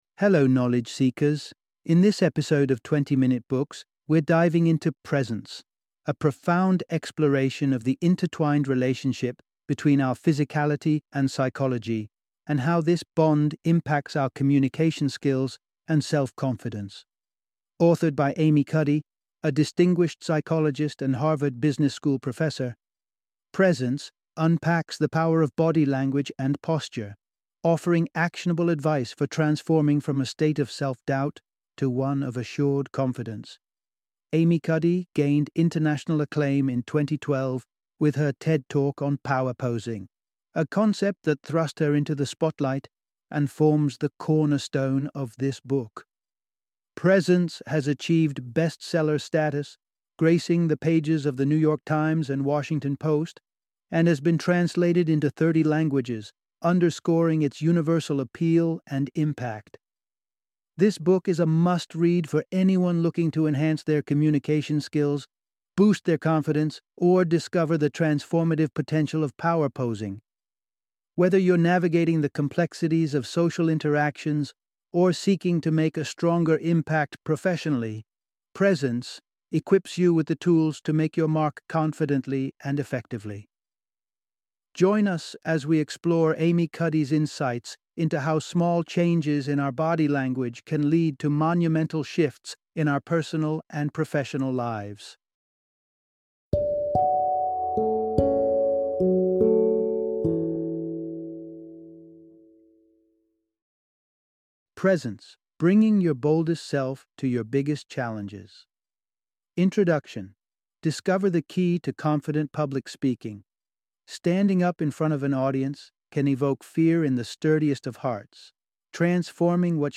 Presence - Audiobook Summary